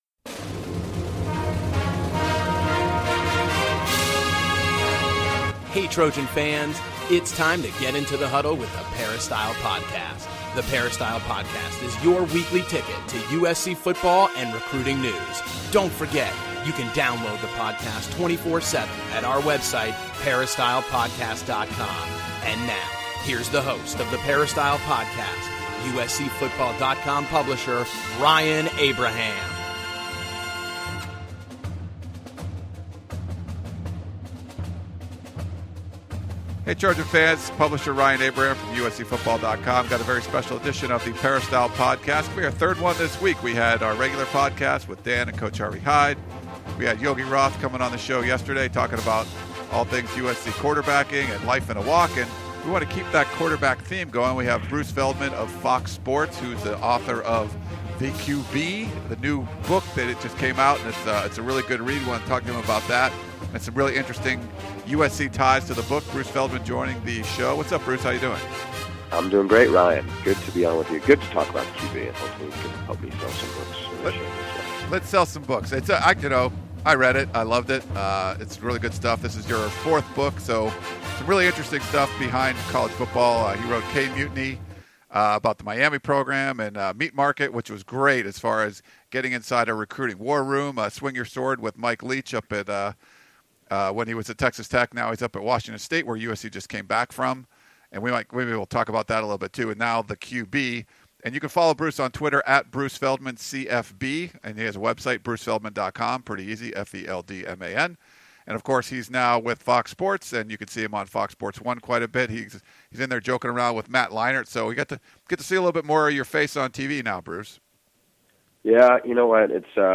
Fox college football analyst Bruce Feldman talks about his new book, The QB